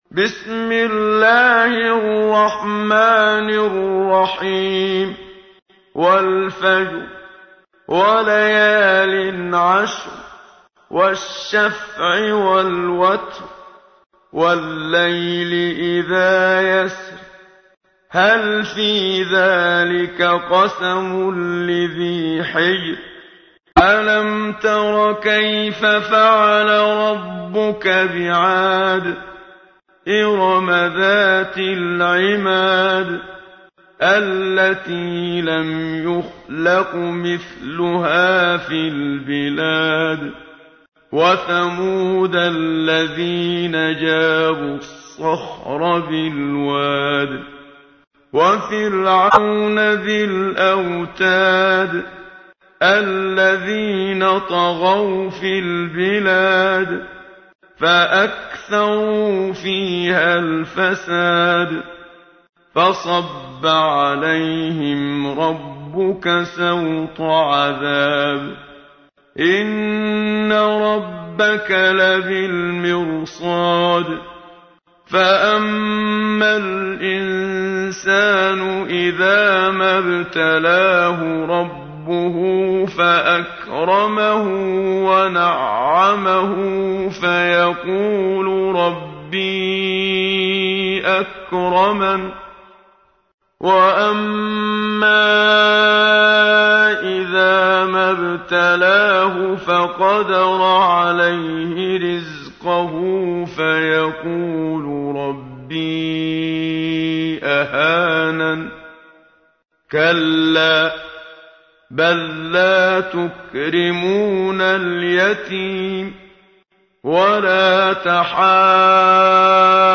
صوت | تلاوت ترتیل «سوره فجر» با صدای منشاوی
در قسمت چهارم، تلاوت ترتیل سوره فجر را با صدای محمد صدیق منشاوی، قاری شهیر مصری می‌شنوید.